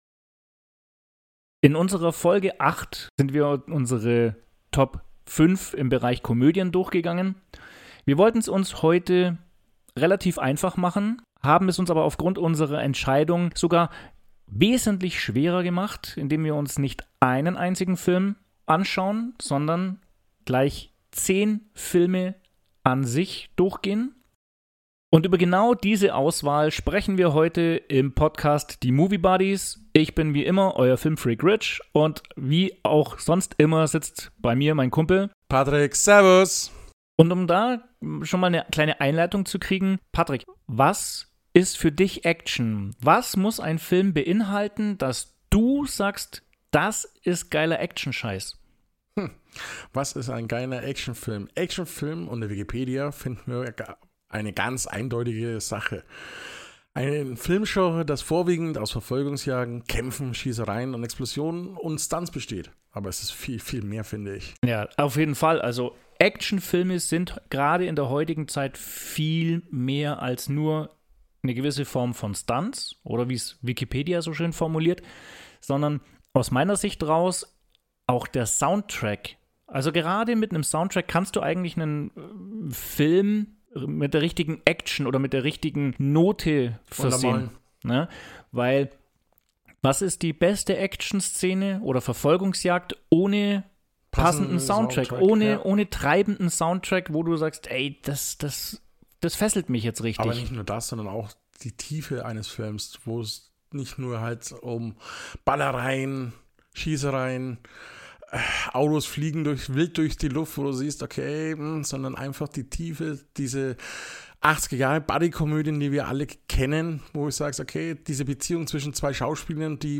Lasst euch überraschen und geniesst einen entspannten Plausch über das Actionkino der Superlative.